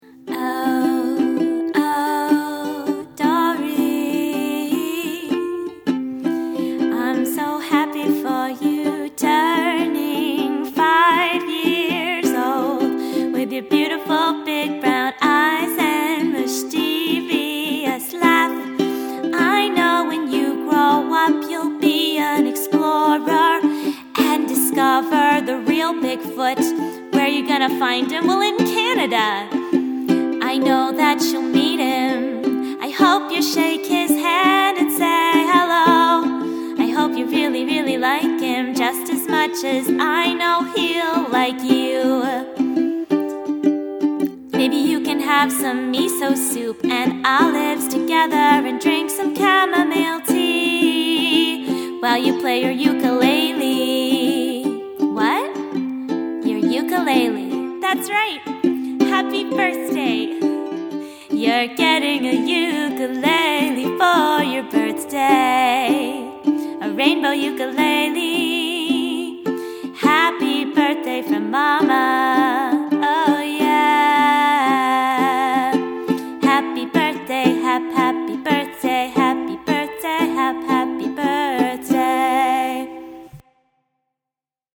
ukulele song and deliver in under a week.